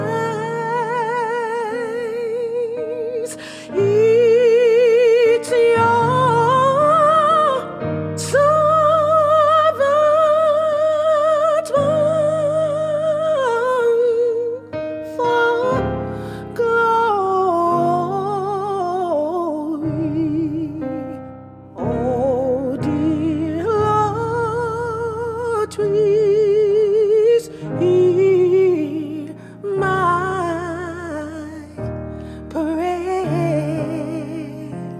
Christian Alternative
Жанр: Альтернатива